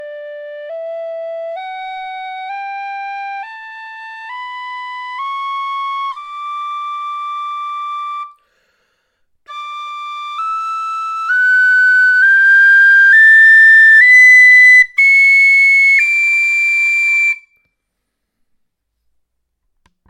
scale.mp3